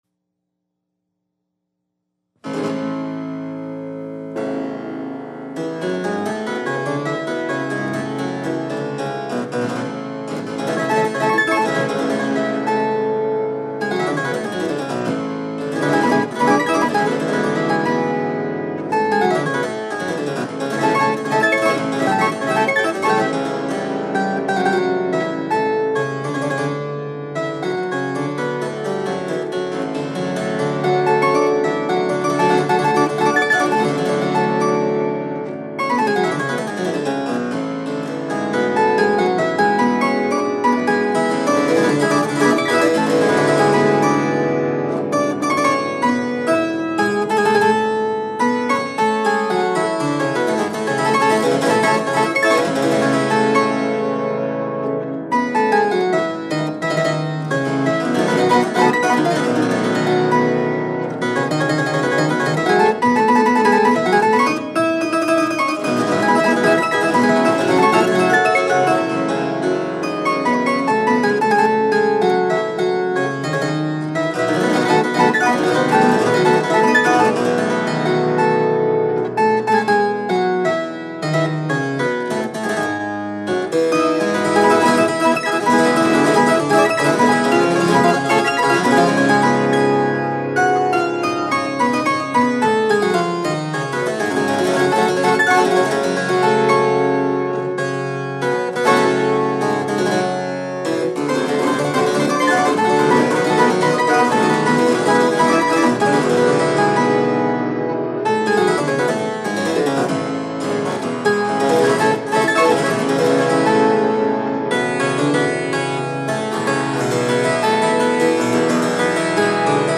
Prelude / Allemande / Courante / Gigue